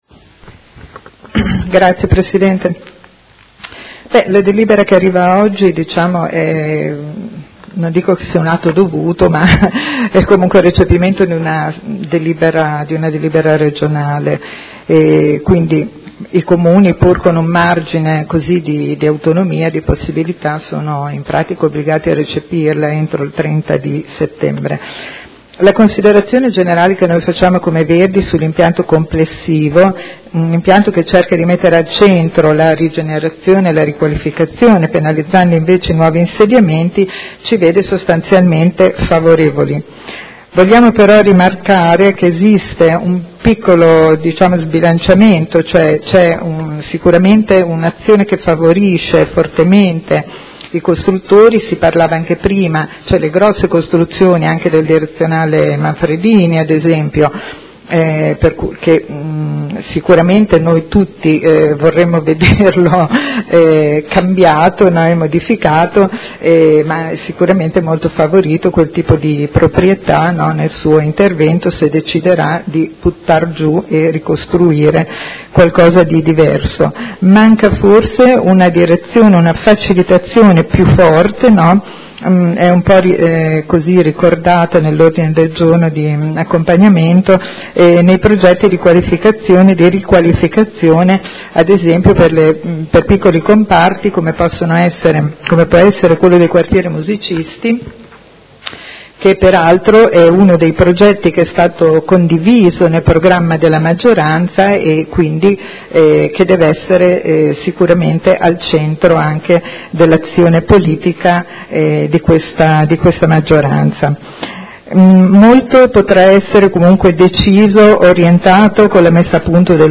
Paola Aime — Sito Audio Consiglio Comunale
Seduta del 26/09/2019.